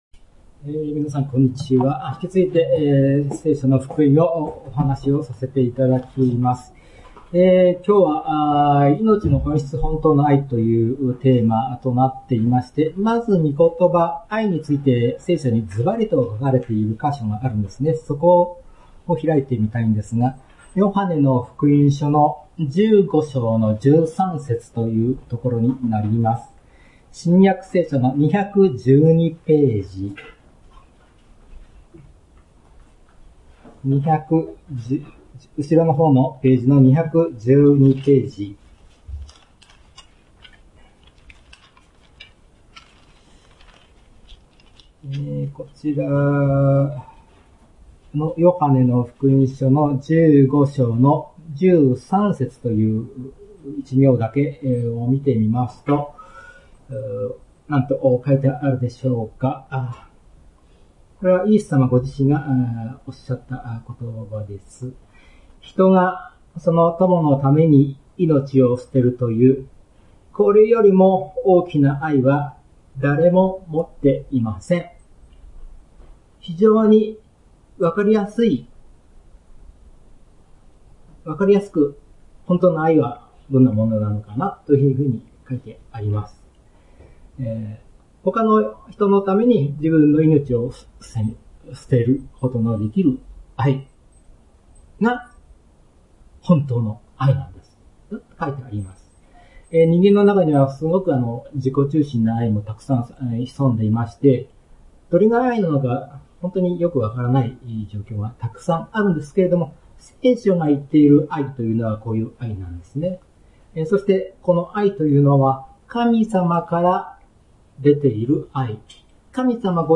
聖書メッセージ